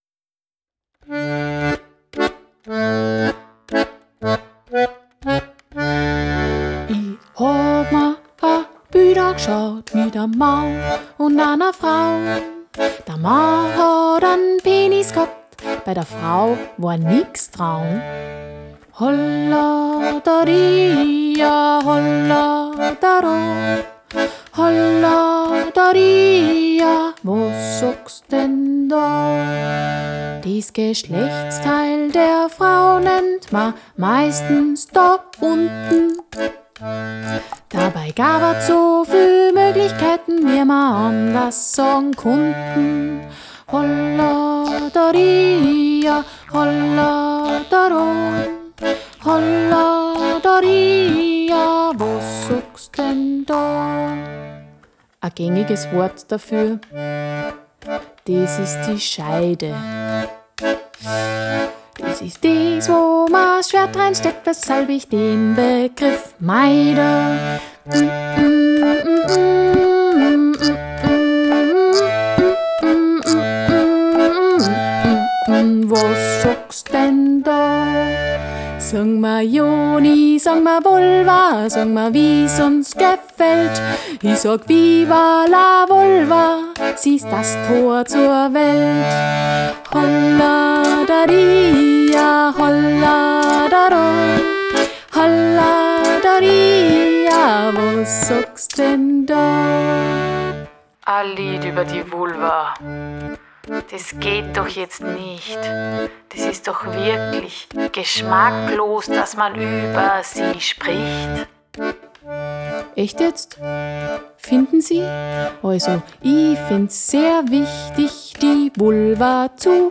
Melodie: überliefert